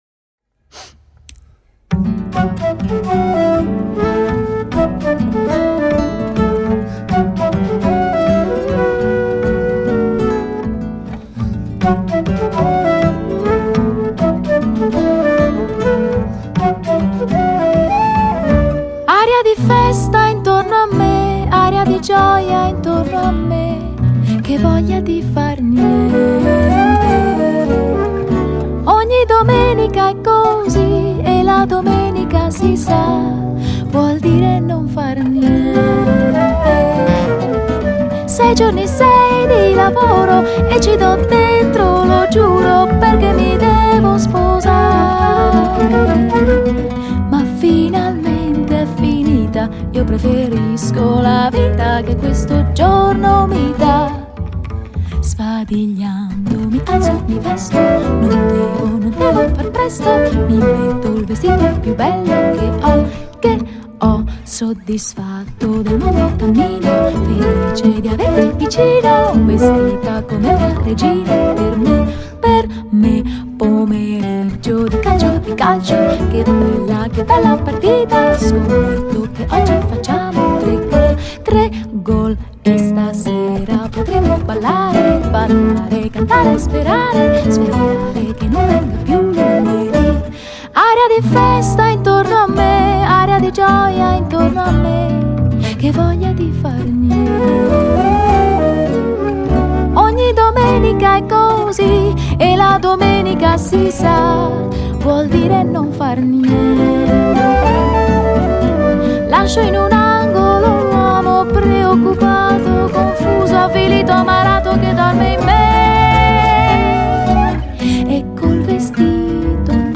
Жанр: Jazz/Pop.